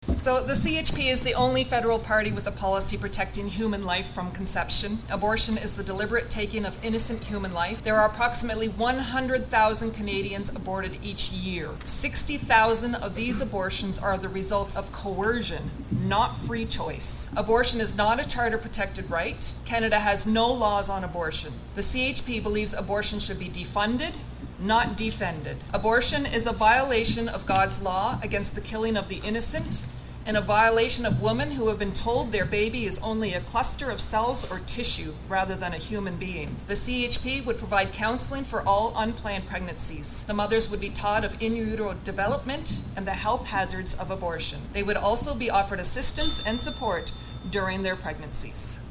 All-candidates debate, hosted by the Simcoe and District Chamber of Commerce